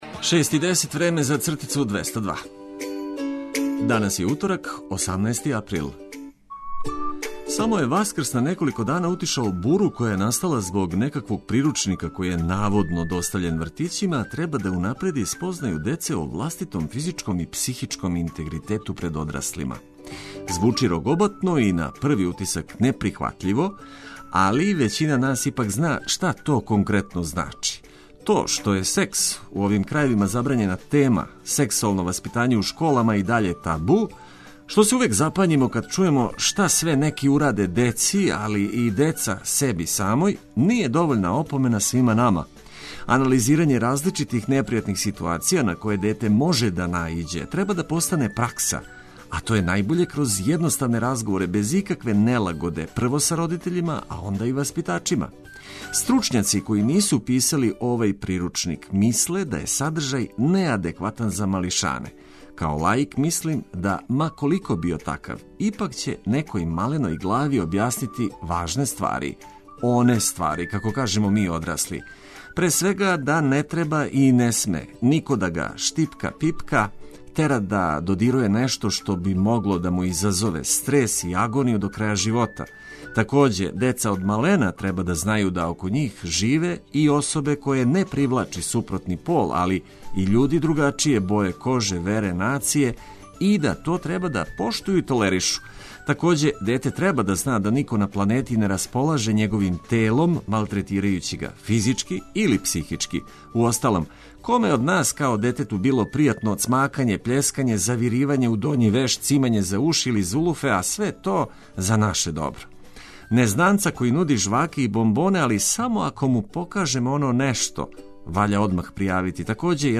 Током јутра најважније информације о приликама у Србији уз веселу музику која ће вам олакшати устајање из кревета и повратак на посао.